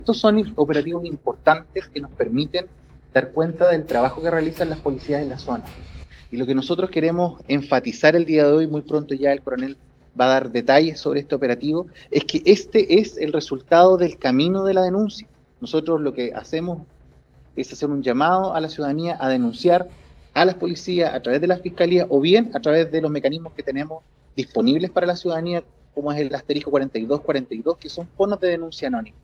Por su parte, el delegado provincial Javier Fuchslocher destacó el trabajo de Carabineros y sobre todo, las denuncias anónimas que permitieron desbaratar estas dos bandas criminales.